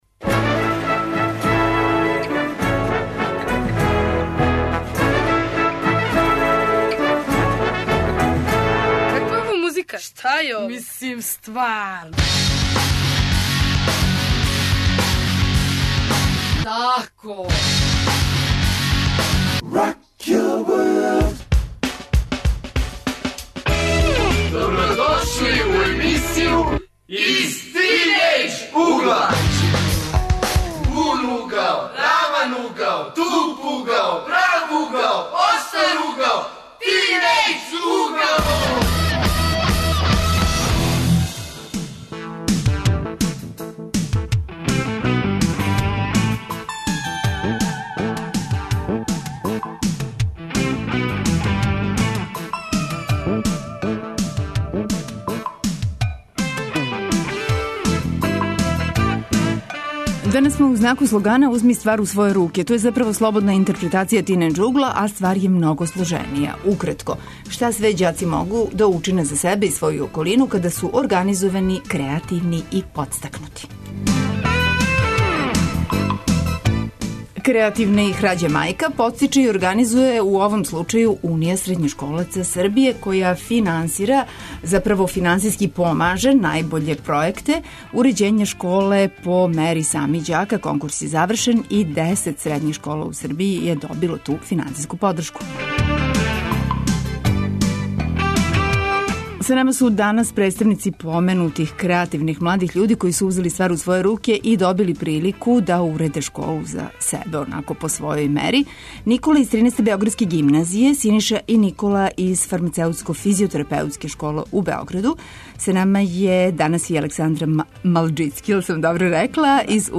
О томе шта све могу ђаци када узму ствари у своје руке, причамо са младим људима, гостима данашње емисије.